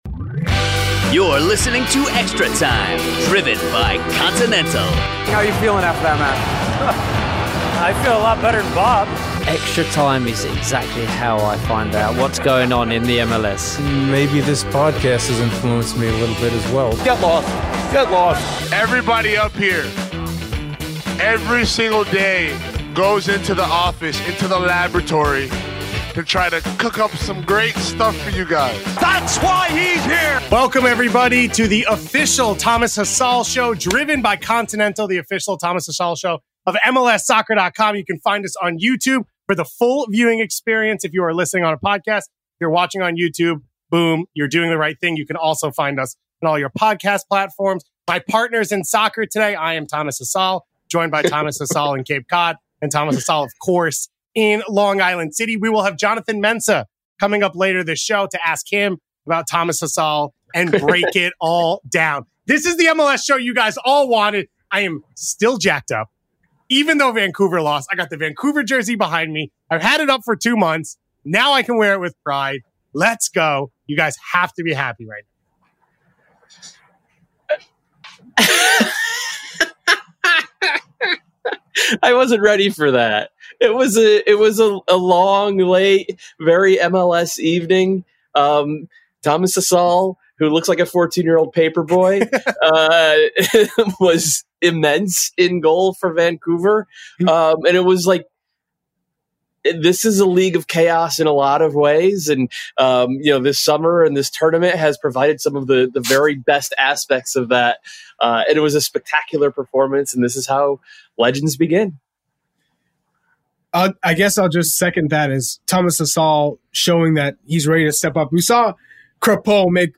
Riding off the high of Sunday night the guys react to the new darling of MLS and talk about what Vancouver can take back from the bubble. Then the guys break down SKC and Philadelphia’s chances going forward.